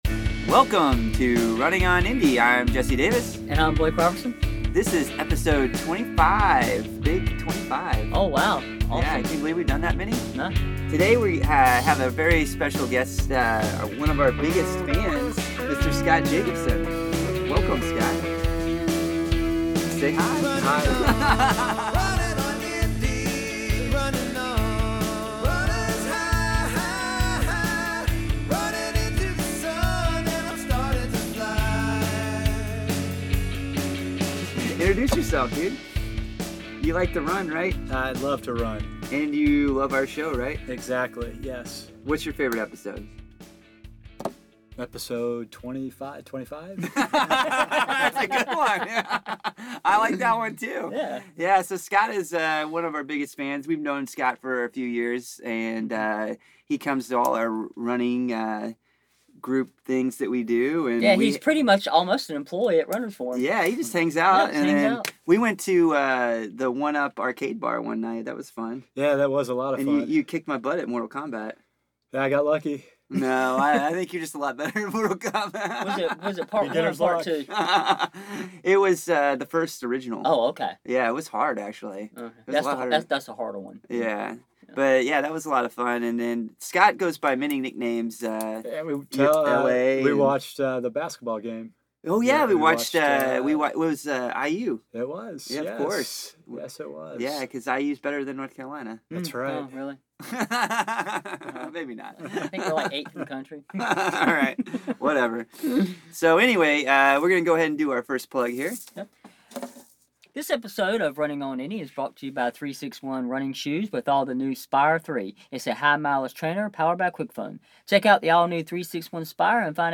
Sneak preview...the more might include us doing our best dog impressions!